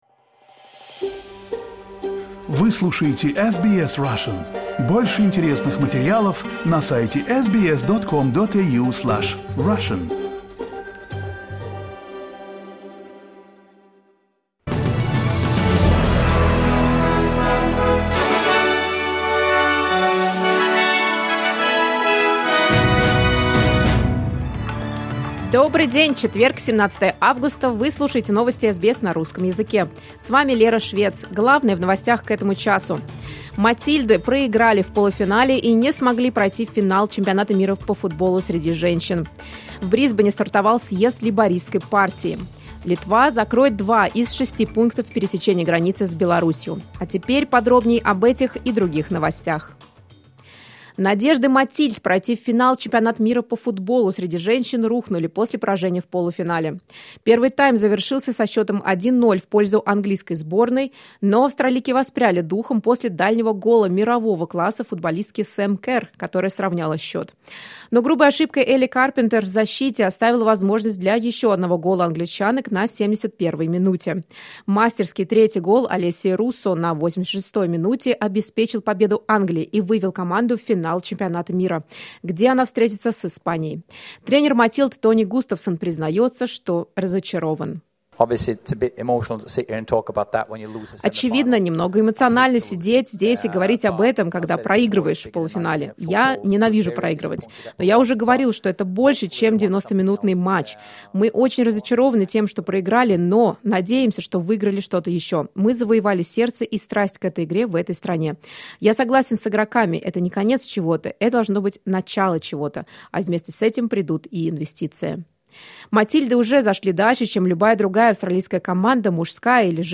SBS news in Russian — 17.08.2023
Listen to the latest news headlines in Australia from SBS Russian